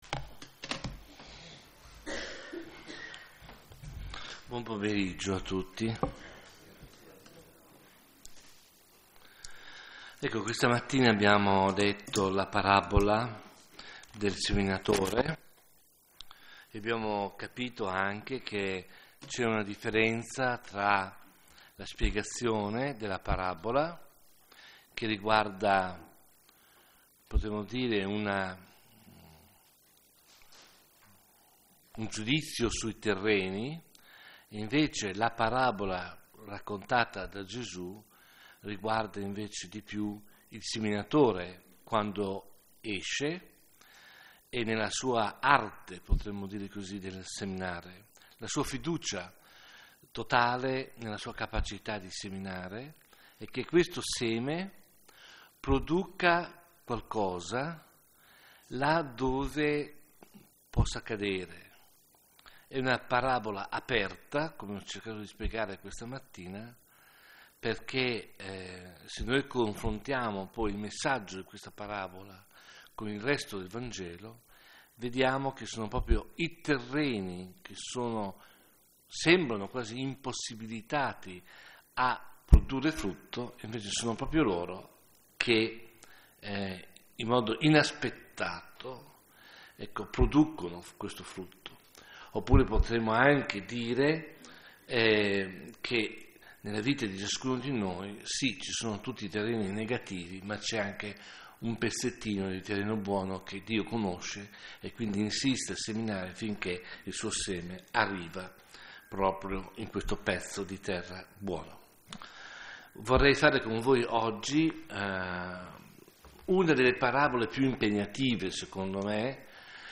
Serie: Meditazione